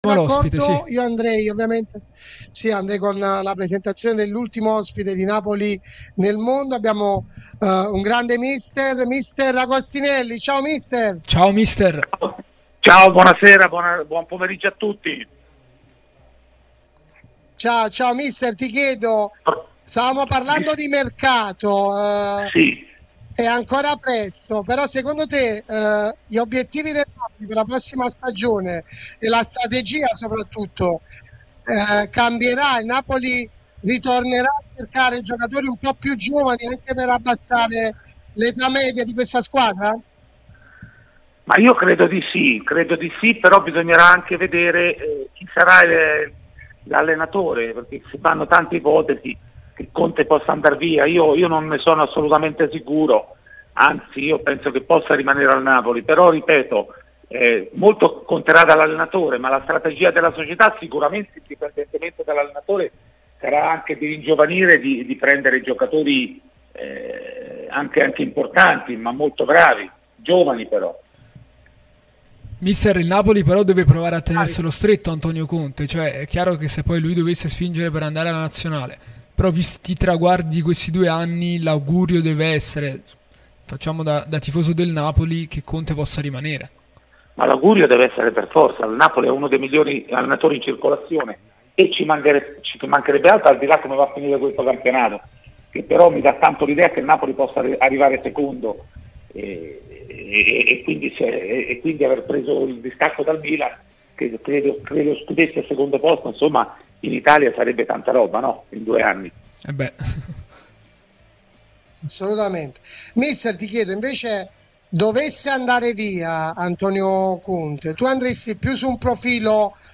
L'ex allenatore del Napoli Andrea Agostinelli è intervenuto su Radio Tutto Napoli, l'unica radio tutta azzurra e live tutto il giorno, che puoi seguire sulle app gratuite (per Iphone o per Android, Android Tv ed LG), in DAB o qui sul sito anche in video.